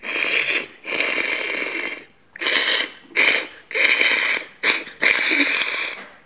ajout des sons enregistrés à l'afk
bruit-animal_22.wav